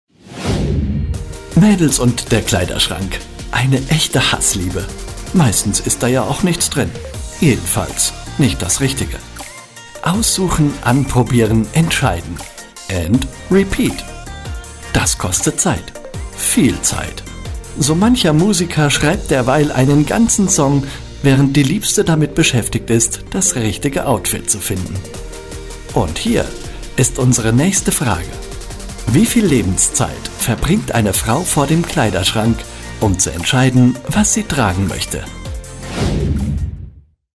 Sprecher, Werbesprecher, Stationvoice